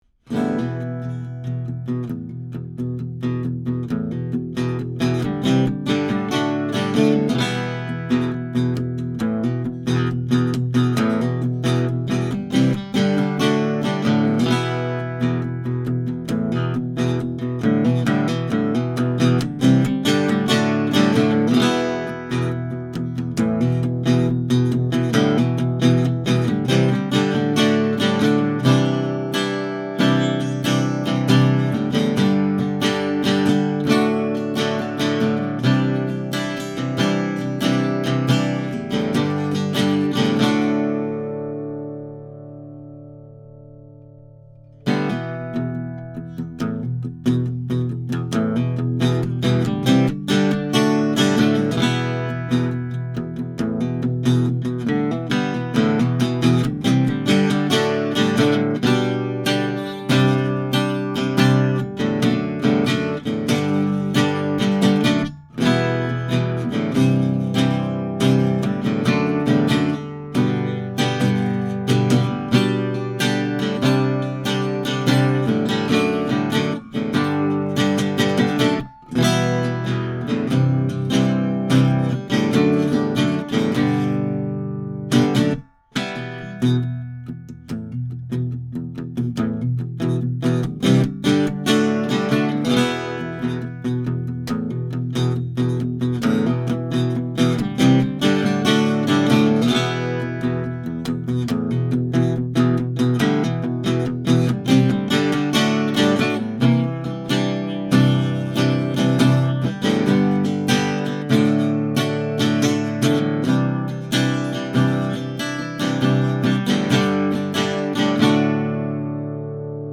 1937 Gibson L7 Acoustic Archtop Guitar
This 17" archtop has the rare picture-frame inlays, and parallel bracing, and has a warmth and clarity that you can only get in a Pre-War archtop.
CLICK HERE for additional 1270 DPI Hi-Res photos of this L7, and here are some MP3s of me playing this guitar, to give you an idea of what to expect. Signal chain is a pair of Neumann U87s through a Flickinger Audio TwinFlicks preamp into a Metric Halo ULN-8 interface: